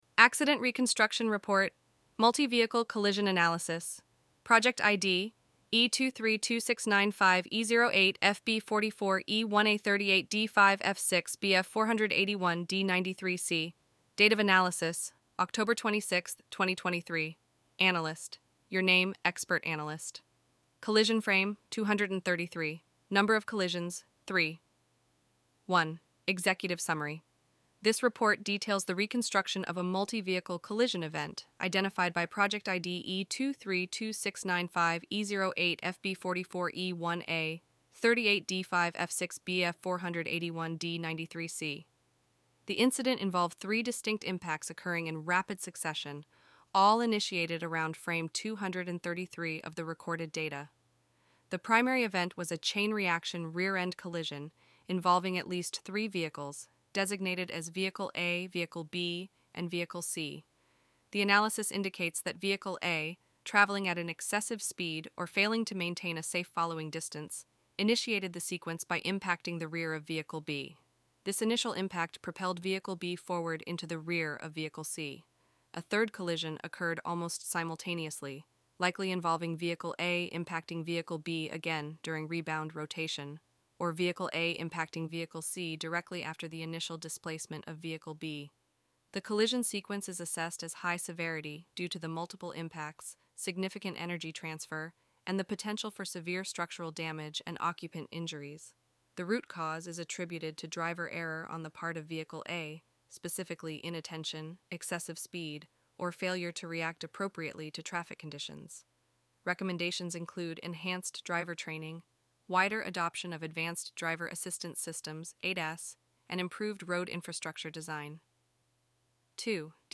To make insights easier to consume, CollisionCloud also produces spoken summaries of each accident.
English Audio Report Sample